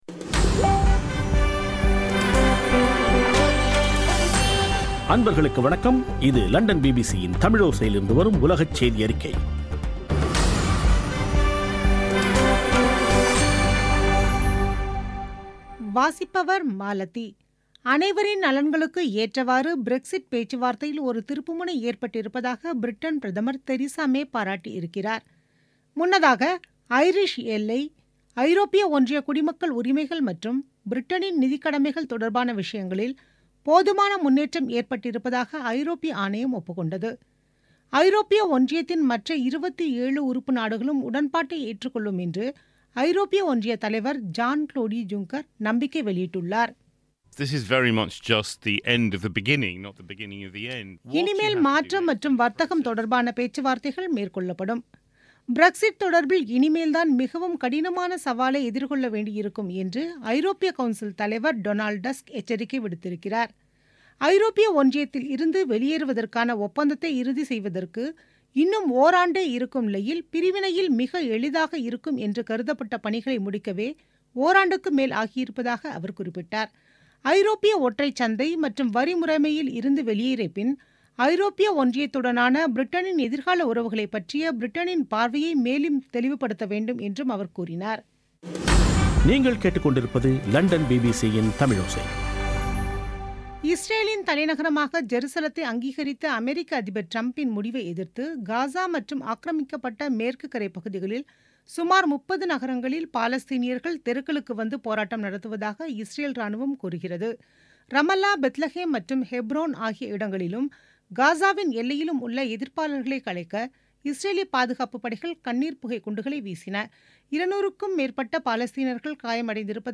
பிபிசி தமிழோசை செய்தியறிக்கை (08/12/2017)